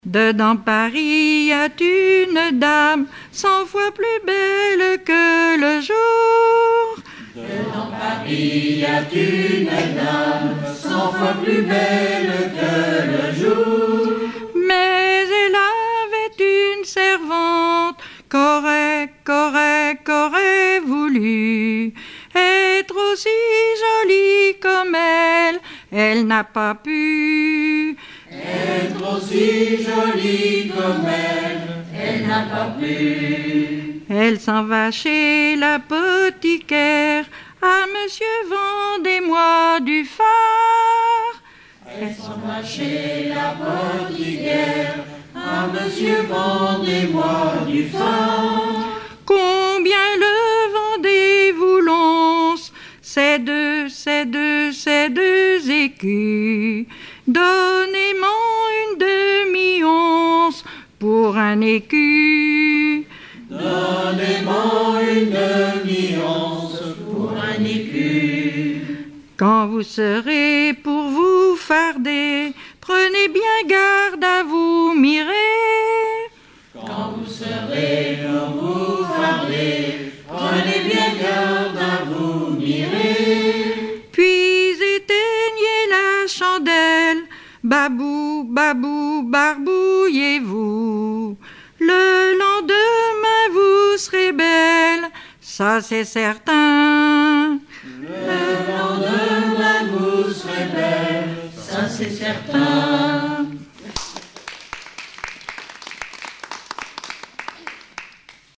Genre strophique
Chansons traditionnelles et populaires
Pièce musicale inédite